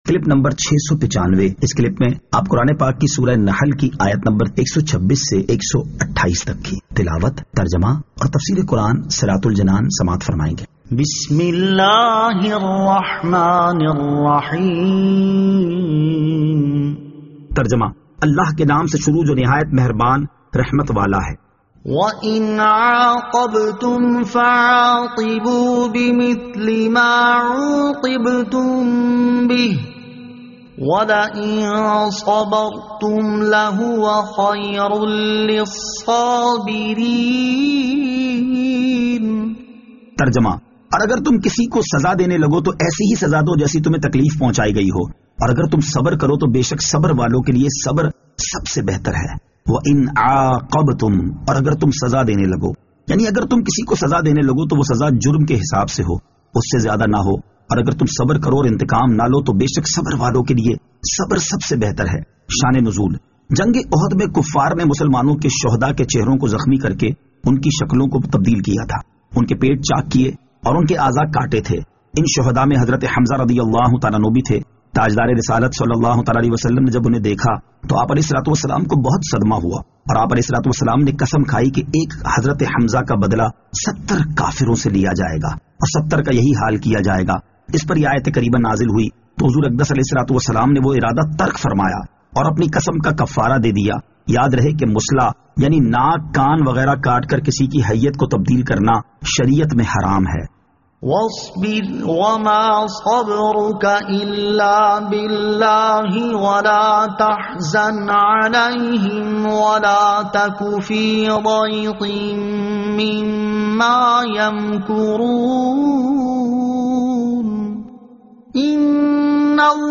Surah An-Nahl Ayat 126 To 128 Tilawat , Tarjama , Tafseer